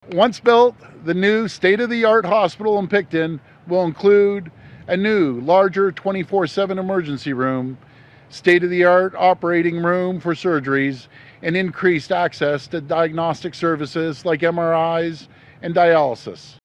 Ontario Premier Doug Ford was in Picton on Friday to make a special health care-related announcement.